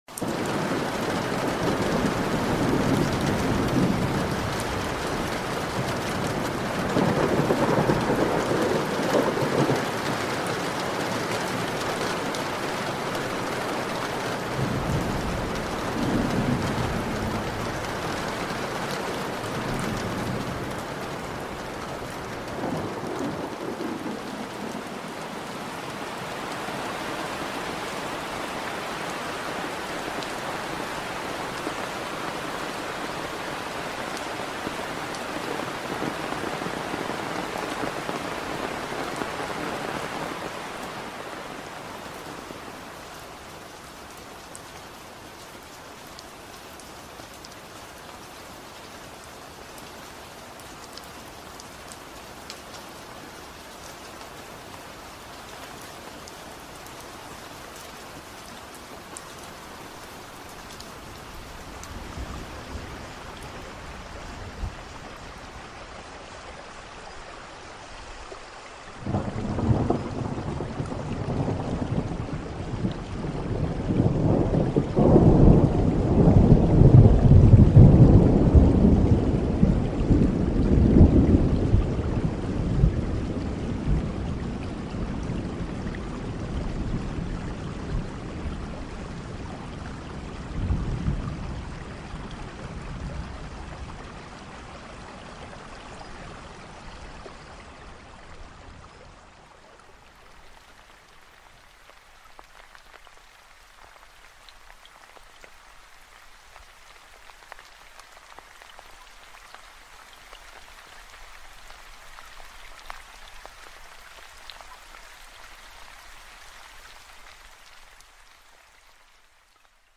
From the calming patter of a soft drizzle to the dramatic roar of a thunderstorm, each layer of this design reflects a different rhythm of nature.